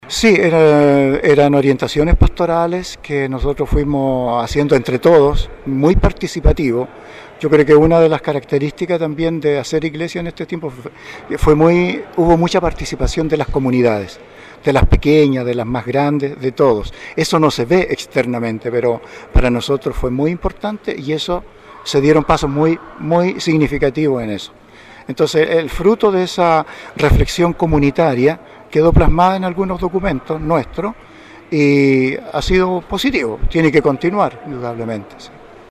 Con la Iglesia Catedral San Mateo completa de fieles, en la tarde de ayer se realizó la Misa-Acción de Gracias por su servicio como pastor de la Diócesisde Monseñor Jorge Concha Cayuqueo.